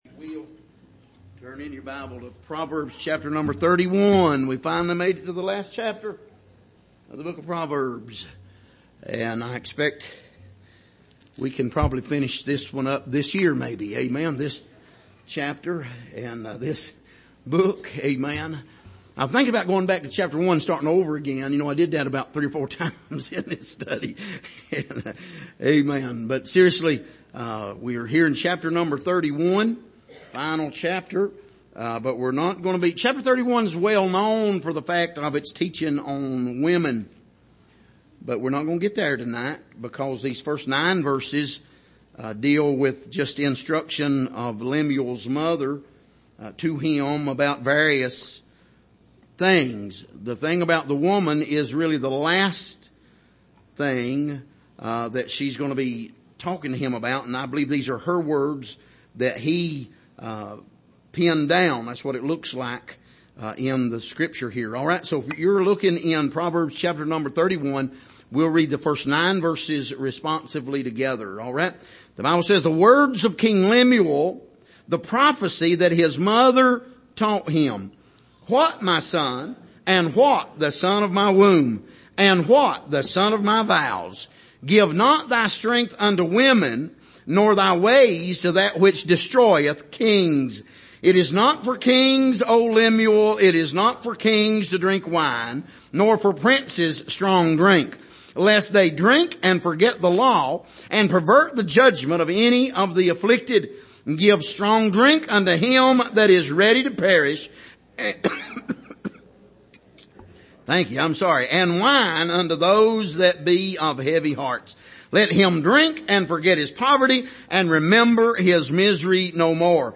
Passage: Proverbs 31:1-9 Service: Sunday Evening